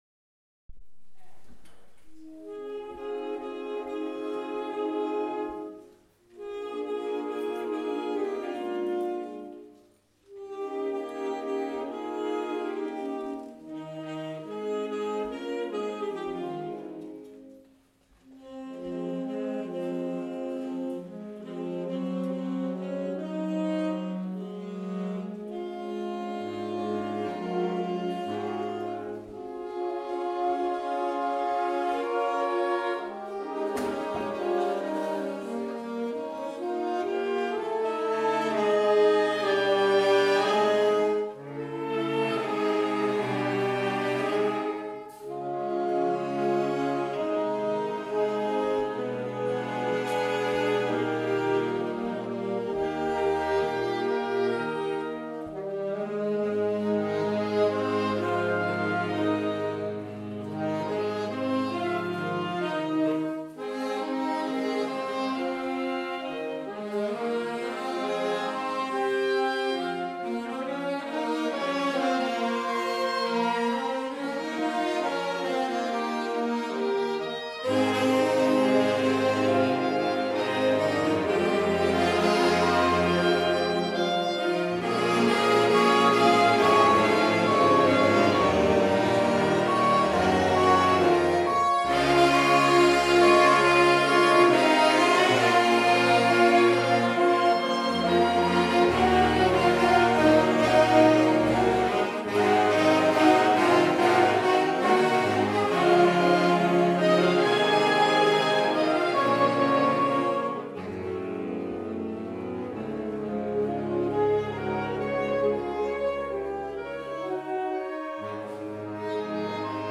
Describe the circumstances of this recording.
A Concert of Wind, Brass and Percussion, April 2015